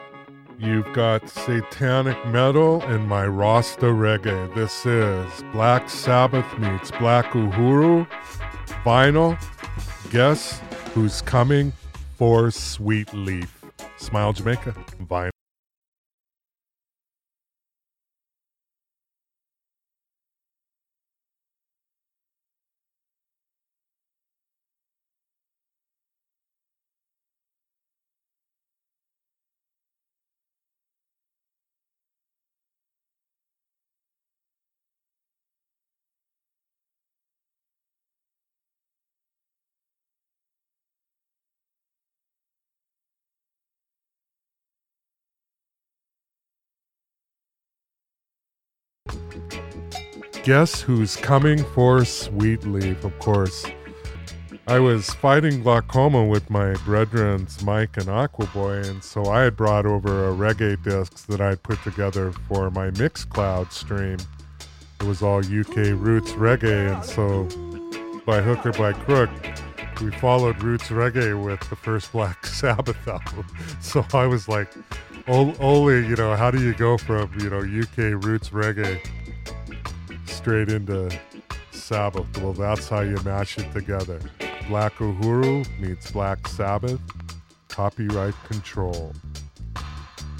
You’ve got Satanic Heavy Metal inna mi Rasta Roots Reggae!